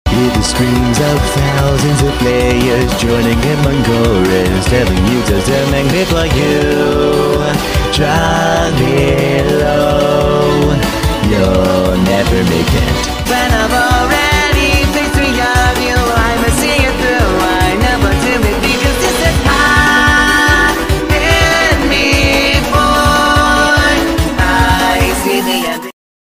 with lyrics and I sing and turn super at the end